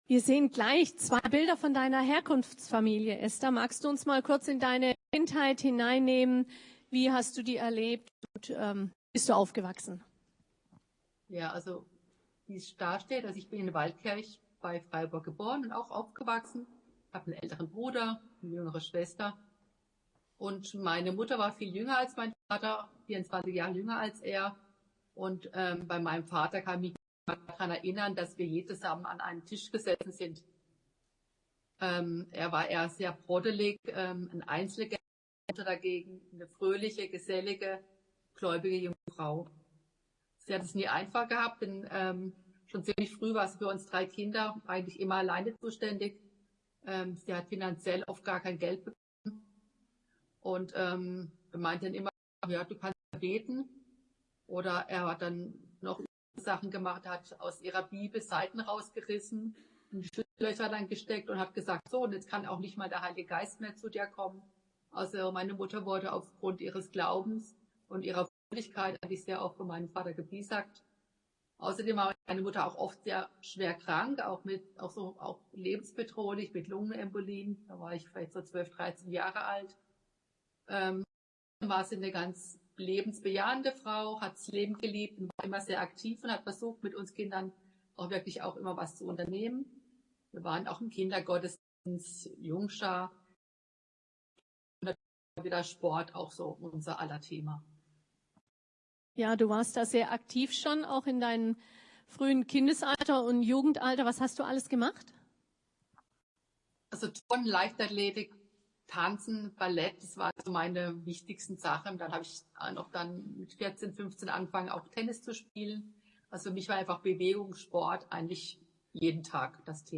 Oktober 2024 Predigt LifeTalk , LifeTalk: Weiterleben Mit dem Laden des Videos akzeptieren Sie die Datenschutzerklärung von YouTube. Mehr erfahren Video laden YouTube immer entsperren Anschauen Anhören Speichern LifeTalk mit Esther Weber: Weiterleben… weil Aufgeben keine Option ist Seit einem schweren Autounfall im Jugend-alter sitzt Esther Weber im Rollstuhl. Das verändert ihr Leben, doch die junge Frau kämpft und wird zur Weltklasse-Rollstuhlfechterin.
LIFETALK LifeTalk ist das etwas andere Gottesdienst-Format der FeG Heidelberg.